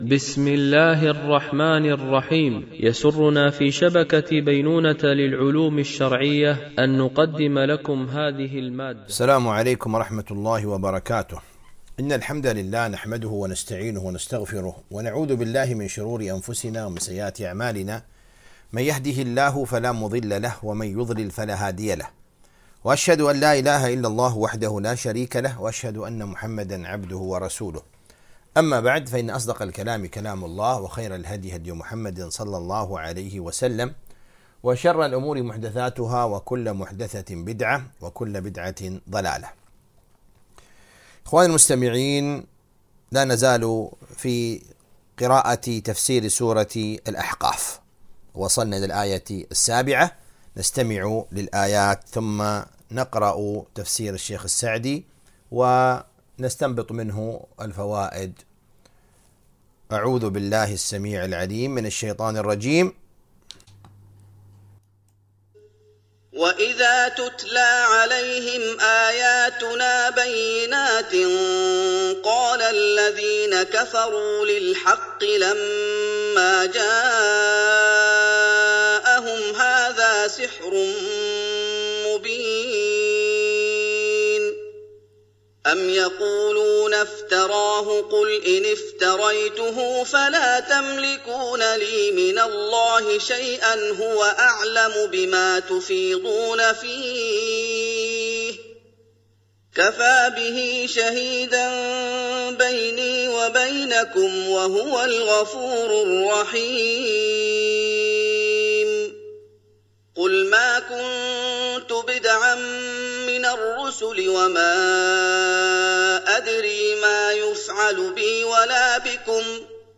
تفسير جزء الذاريات والأحقاف ـ الدرس 02 ( سورة الأحقاف )